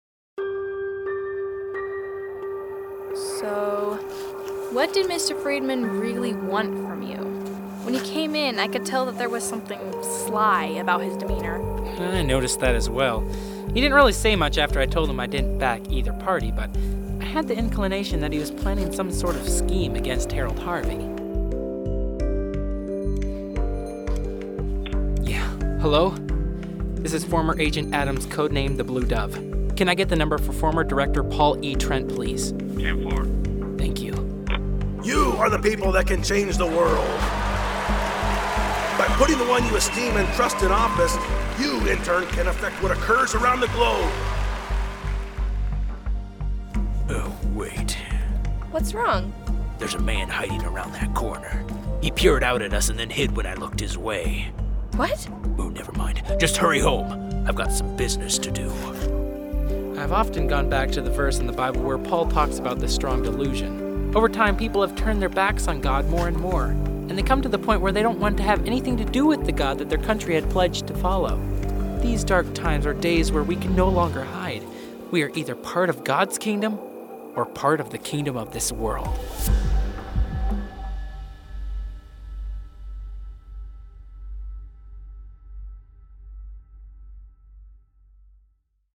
The album seeks to highlight and encourage Anabaptist values through audio drama similar to Adventures in Odyssey.
The-Strong-Delusion-Audio-Trailer.mp3